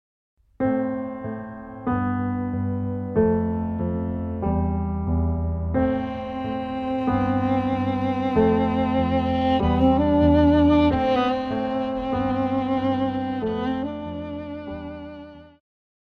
古典,流行
钢琴
演奏曲
世界音乐
仅伴奏
没有主奏
没有节拍器